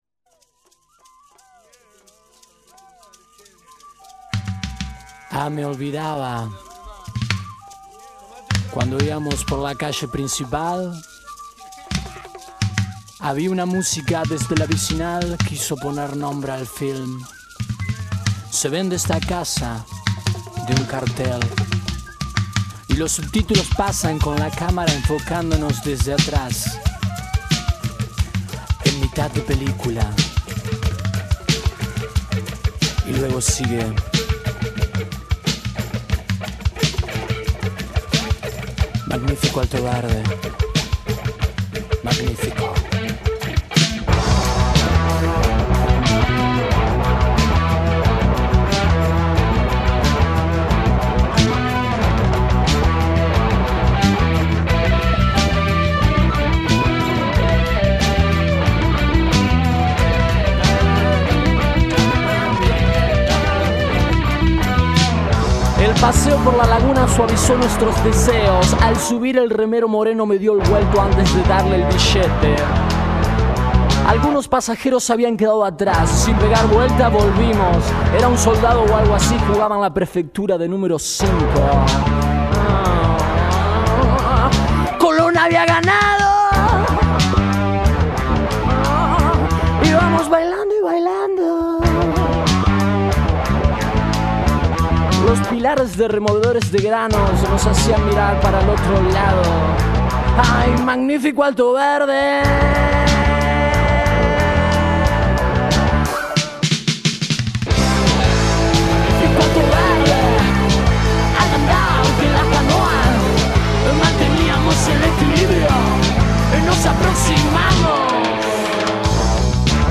voz, guitarra y armónica
bajo y coros.
batería y percusión.